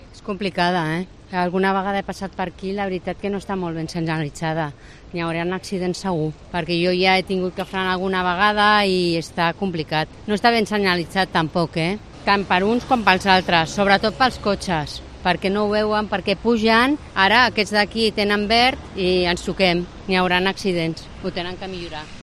Usuaria de bicicleta